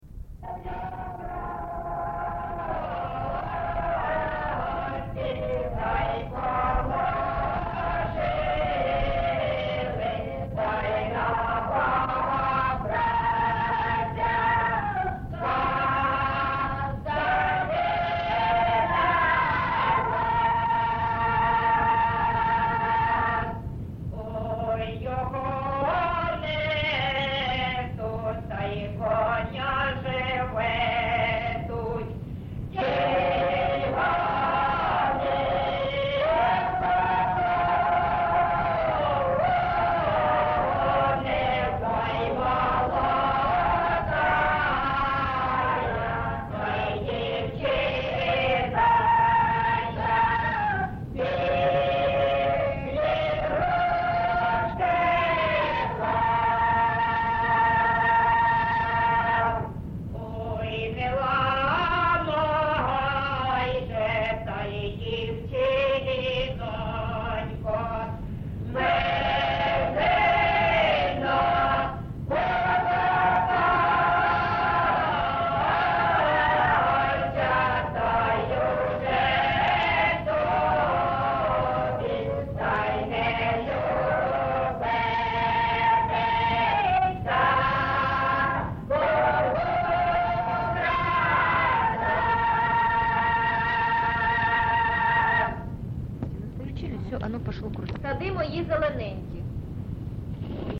ЖанрПісні з особистого та родинного життя, Козацькі, Солдатські
Місце записус. Семенівка, Краматорський район, Донецька обл., Україна, Слобожанщина